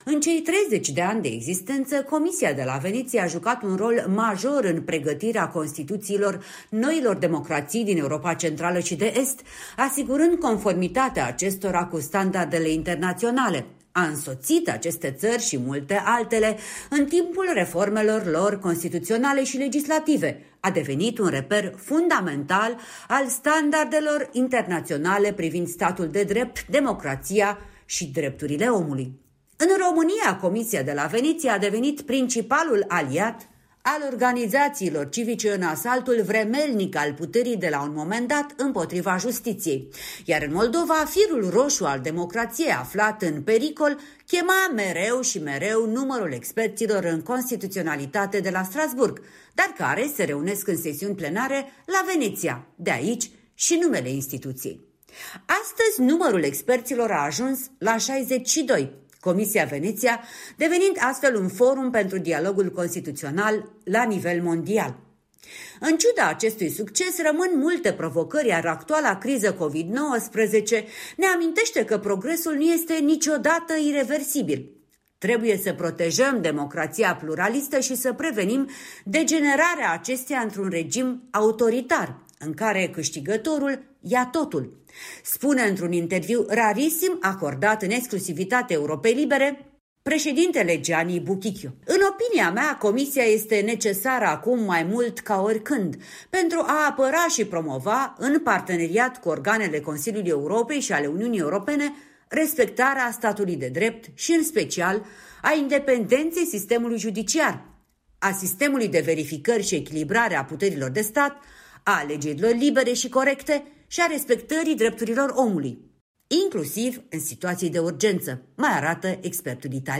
Interviu cu Gianni Buquicchio, președintele Comisiei de la Veneția